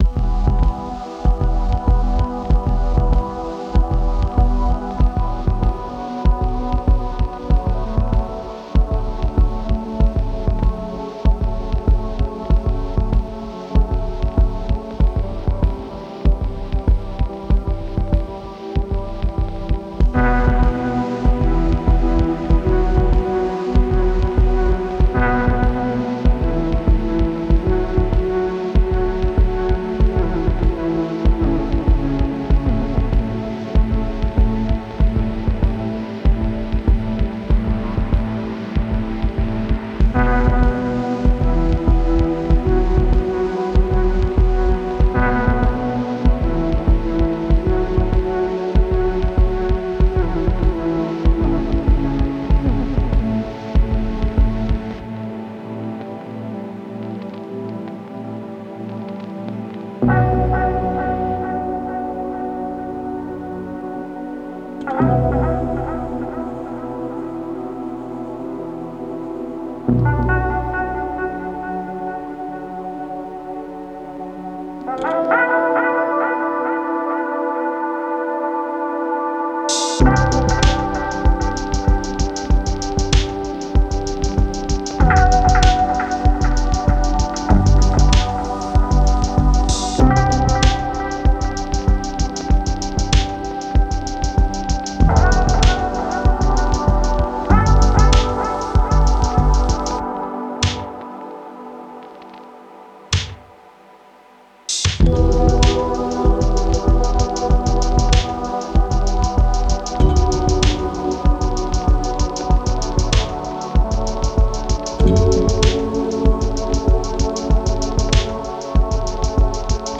Cinematic Electronica